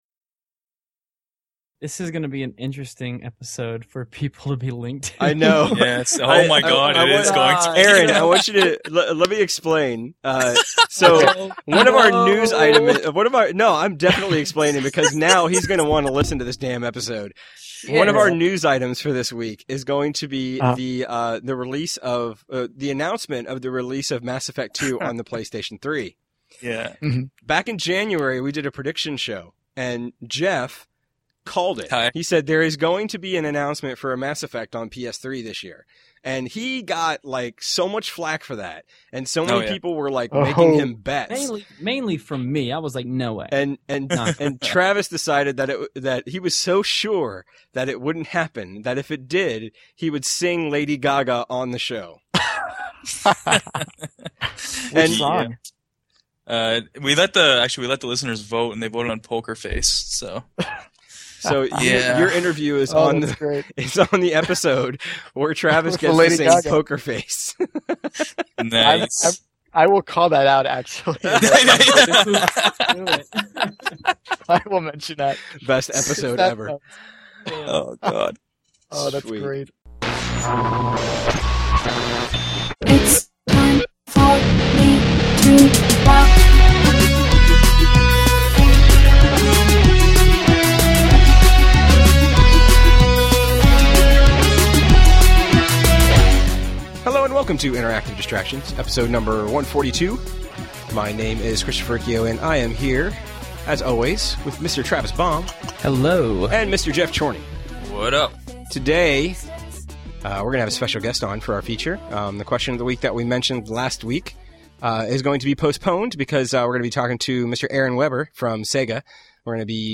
Regular crew plus a member of Sega’s community management team during the feature.